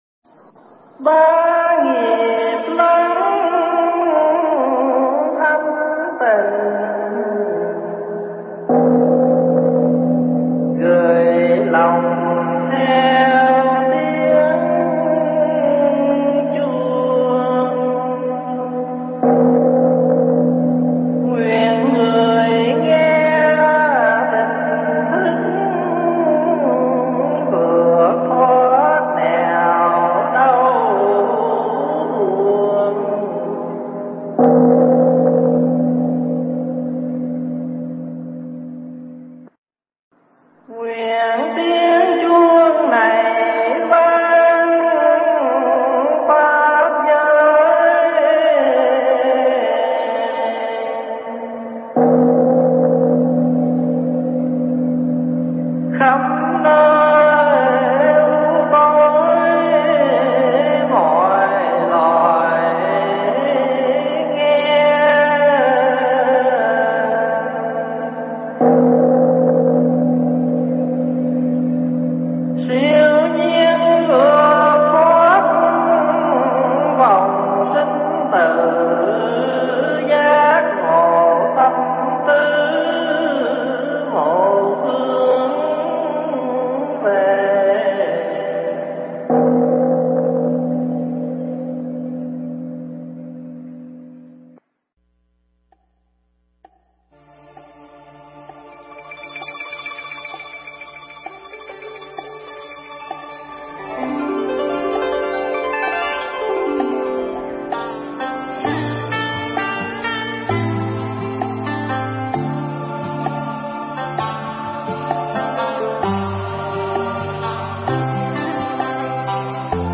Nghe Mp3 thuyết pháp Bốn Điều Cần Có
thuyết giảng tại Tu Viện Trúc Lâm